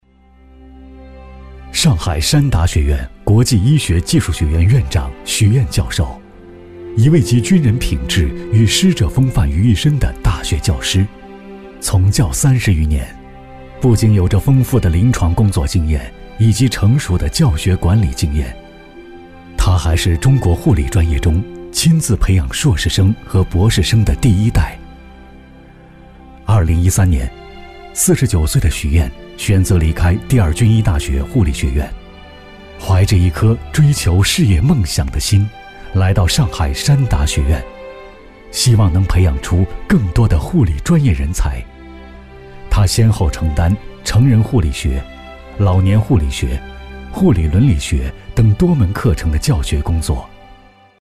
人物男182号（温暖讲述）
年轻时尚 人物专题
青年男中音，年轻时尚，激昂大气，浑厚温暖，激情电购，自述等。